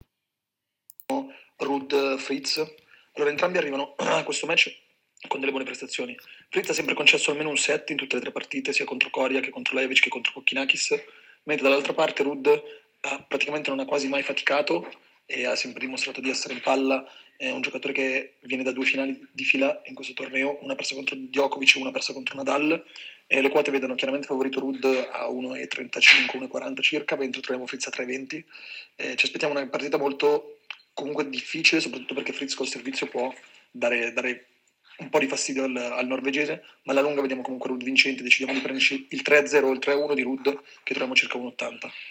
In questo audio le idee del nostro esperto sulle scommesse di Tennis